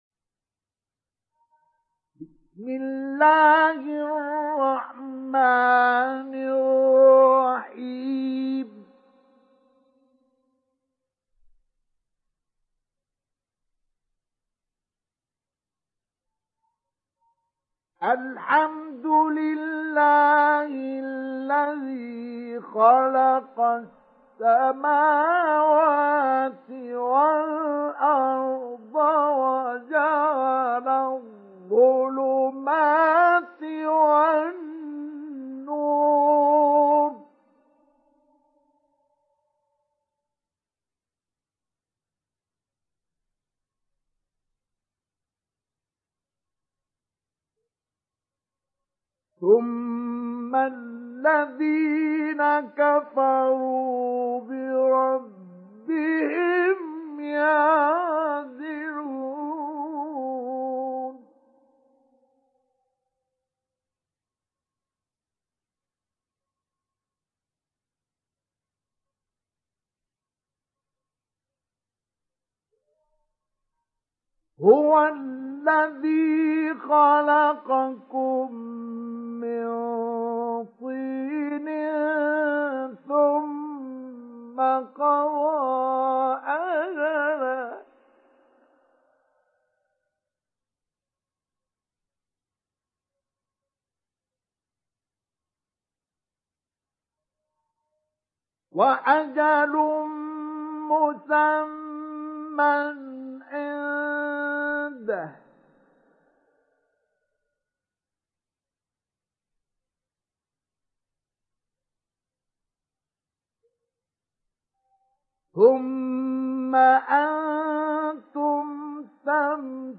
ডাউনলোড সূরা আল-আন‘আম Mustafa Ismail Mujawwad